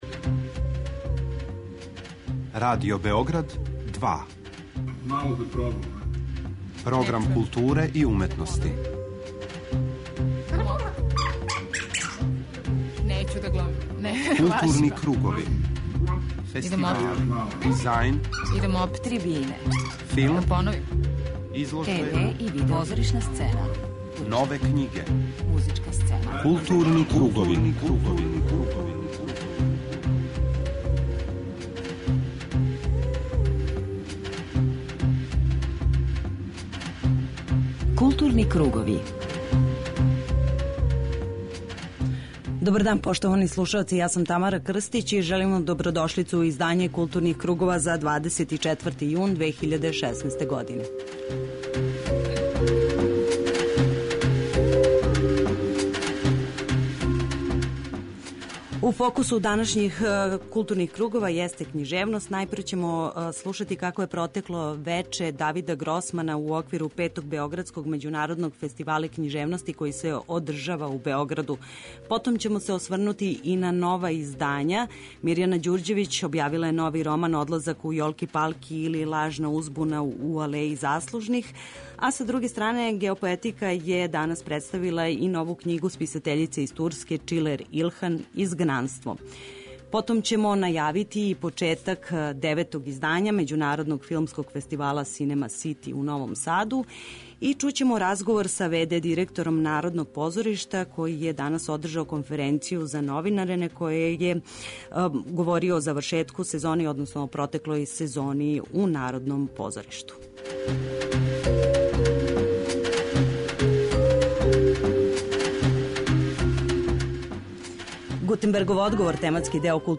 преузми : 41.32 MB Културни кругови Autor: Група аутора Централна културно-уметничка емисија Радио Београда 2.